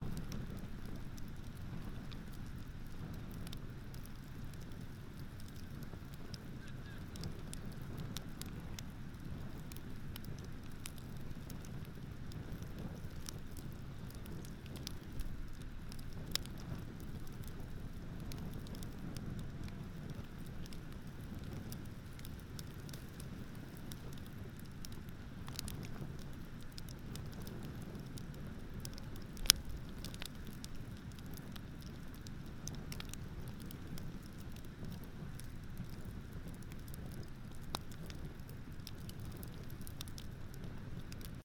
A Small Fire Crackling.ogg